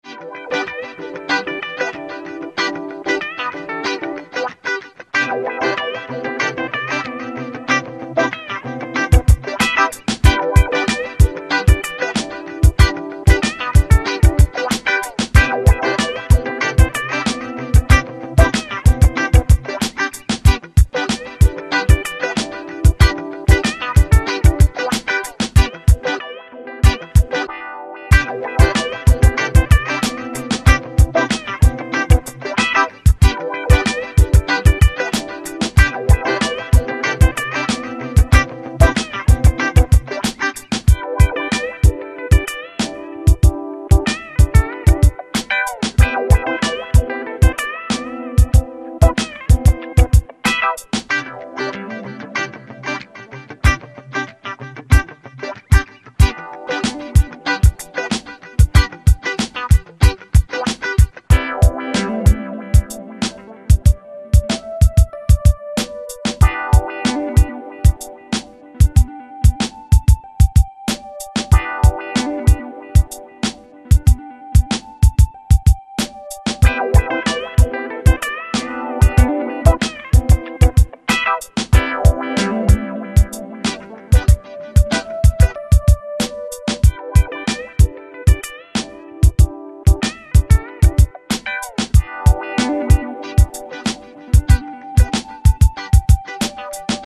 от скуки игрАлся сэмплами воть что получилось просто так :)))